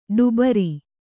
♪ ḍubari